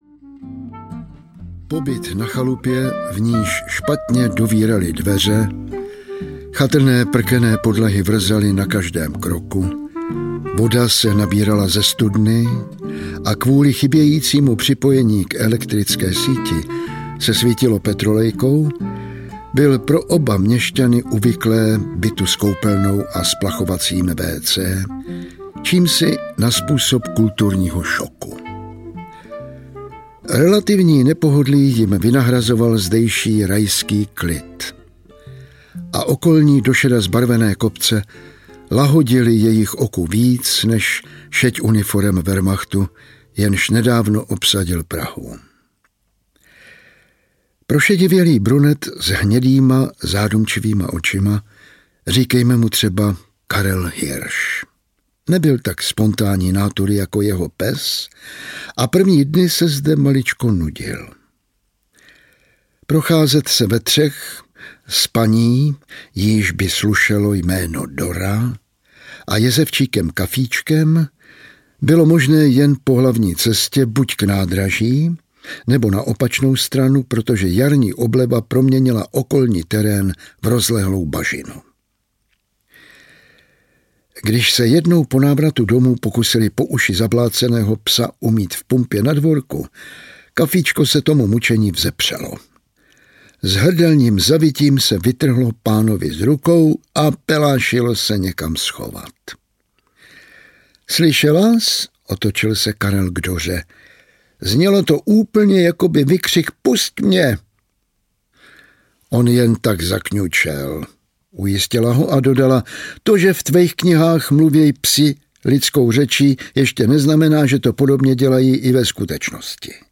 Rozsypaná slova audiokniha
Ukázka z knihy